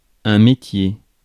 Ääntäminen
UK : IPA : /ˈtɹeɪd/ US : IPA : [tɹeɪd]